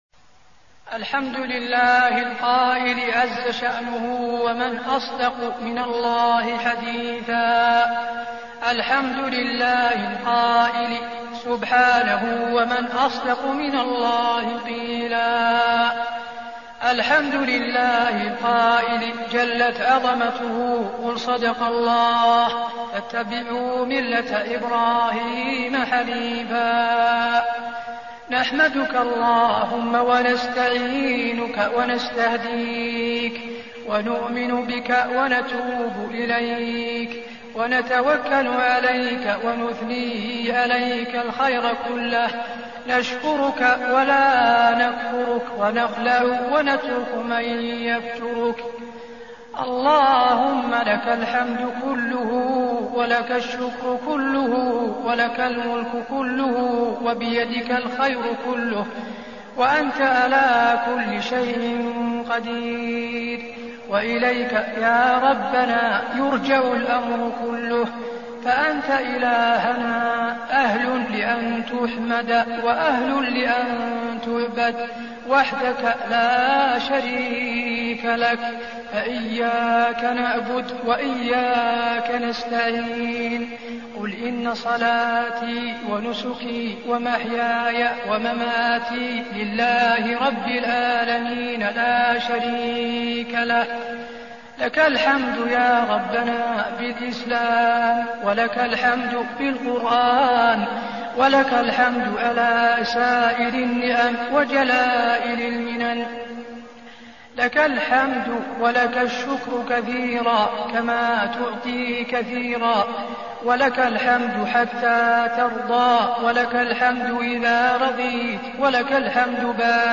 دعاء ختم القرآن
المكان: المسجد النبوي دعاء ختم القرآن